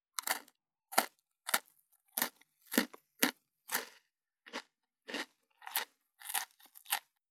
21.スナック菓子・咀嚼音【無料効果音】
ASMR